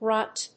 /rˈʌnt(米国英語)/